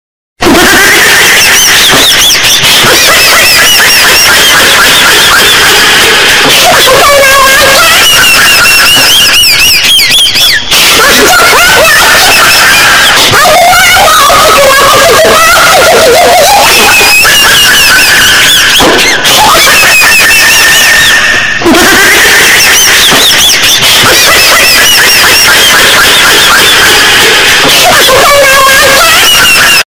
Categoria: Risadas
Esse é um som engraçado de meme de gargalhada que é amado por muita gente nas redes sociais.